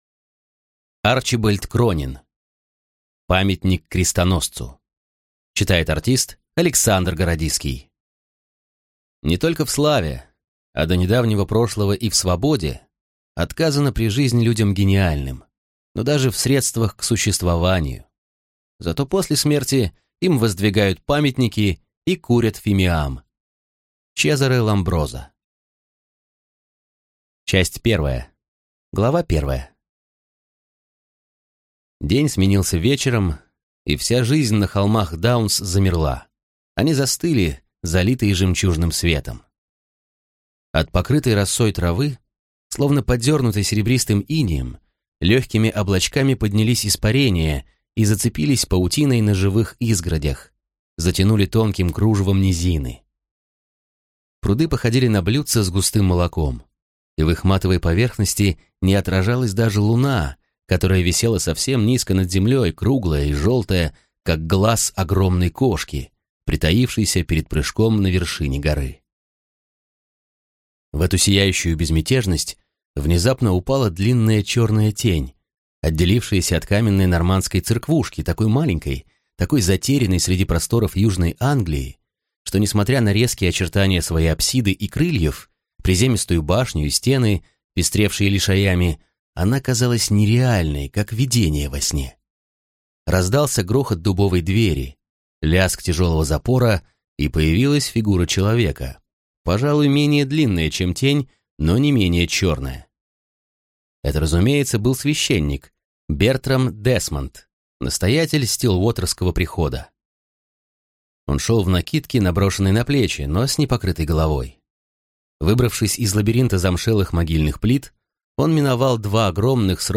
Аудиокнига Памятник крестоносцу | Библиотека аудиокниг